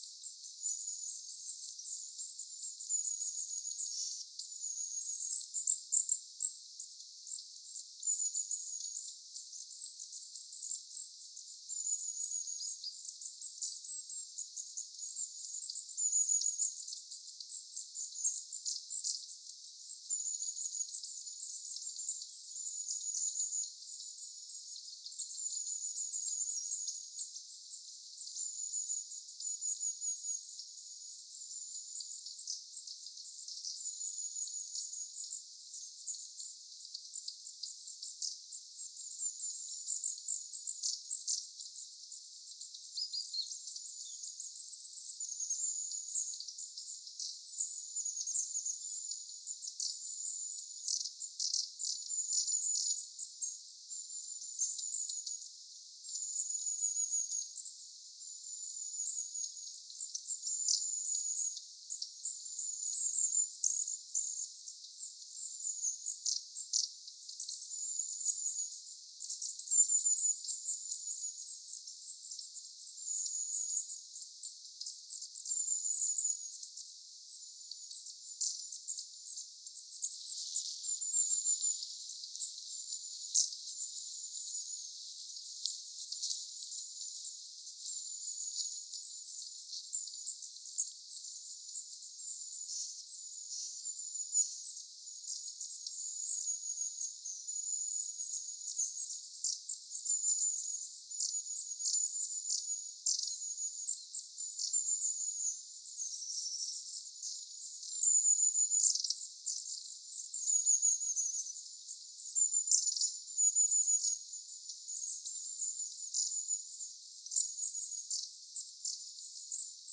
muutolla olevan pyrstötiaisparven ääntelyä
muutolla_olevan_pyrstotiaisparven_aantelya_noin_10_minuuttia.mp3